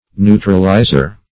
Neutralizer \Neu"tral*i`zer\, n.